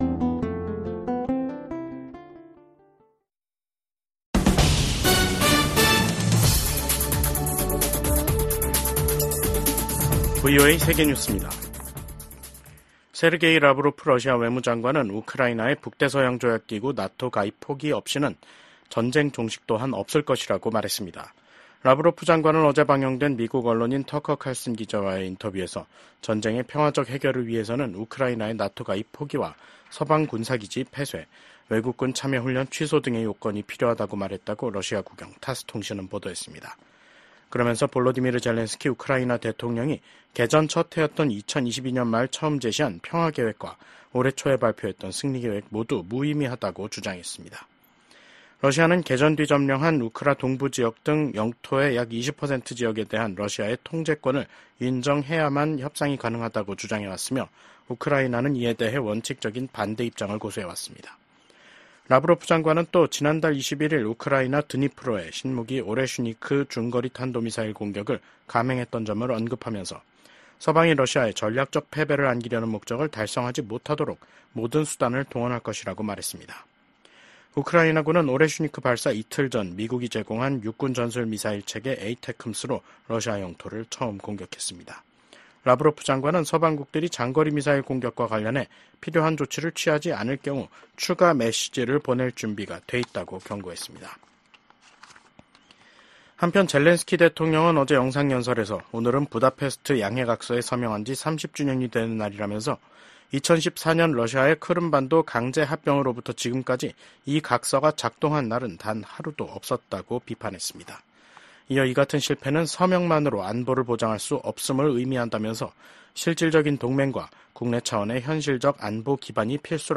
VOA 한국어 간판 뉴스 프로그램 '뉴스 투데이', 2024년 12월 6일 2부 방송입니다. 윤석열 대통령 탄핵소추안에 대한 국회 표결을 하루 앞두고 한국 내 정국은 최고조의 긴장으로 치닫고 있습니다. 미국 국무부는 한국이 대통령 탄핵 절차에 돌입한 것과 관련해 한국의 법치와 민주주의를 계속 지지할 것이라고 밝혔습니다. 한국의 계엄 사태와 관련해 주한미군 태세에는 변함이 없다고 미국 국방부가 강조했습니다.